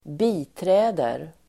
Uttal: [²b'i:trä:der]